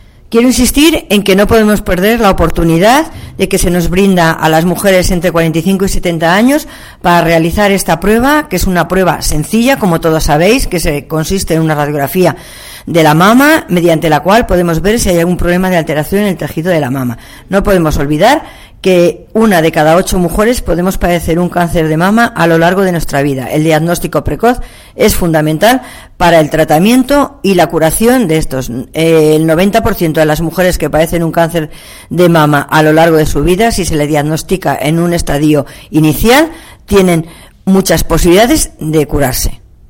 La directora provincial de Sanidad en Guadalajara, Margarita Gascueña, habla de la importancia de la campaña de detección precoz de cáncer de mama.